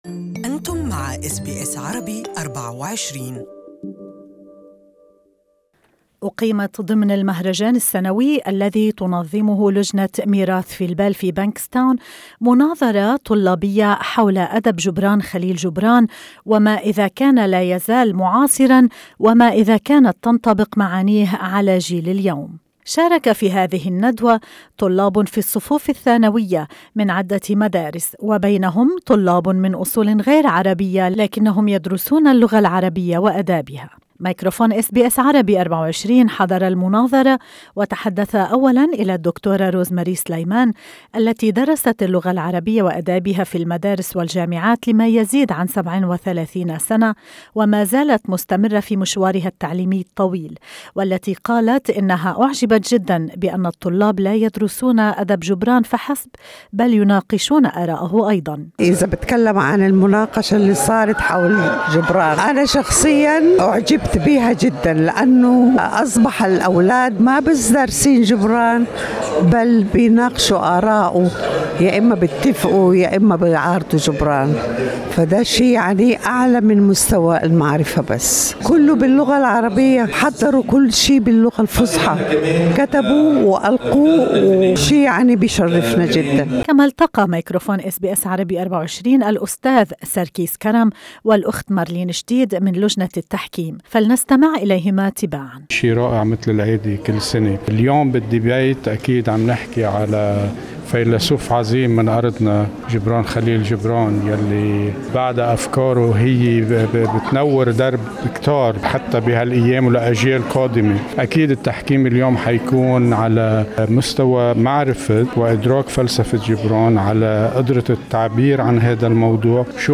مناظرة طلابية في سيدني تناقش فلسفة جبران المتعلقة بالزواج والمحبة والأبناء